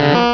Cri de Ramoloss dans Pokémon Rubis et Saphir.